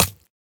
Minecraft Version Minecraft Version latest Latest Release | Latest Snapshot latest / assets / minecraft / sounds / item / trident / pierce2.ogg Compare With Compare With Latest Release | Latest Snapshot
pierce2.ogg